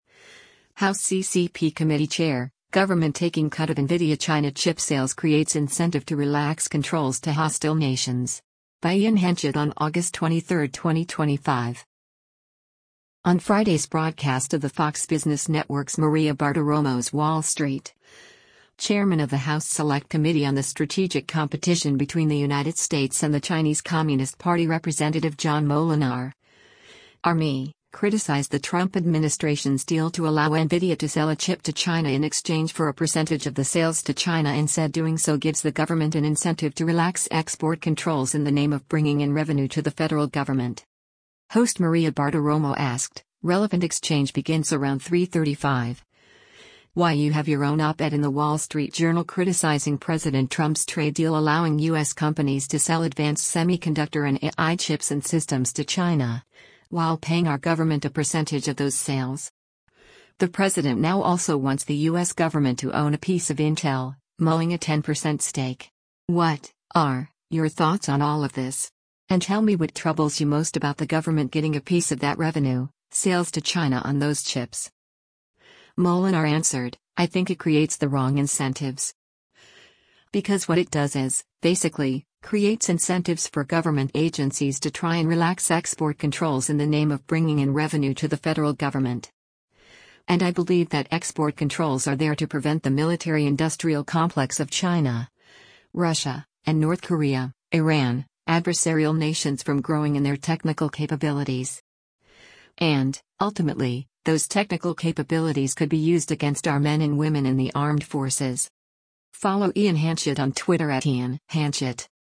On Friday’s broadcast of the Fox Business Network’s “Maria Bartiromo’s Wall Street,” Chairman of the House Select Committee on the Strategic Competition Between the United States and the Chinese Communist Party Rep. John Moolenaar (R-MI) criticized the Trump administration’s deal to allow NVIDIA to sell a chip to China in exchange for a percentage of the sales to China and said doing so gives the government an incentive to “relax export controls in the name of bringing in revenue to the federal government.”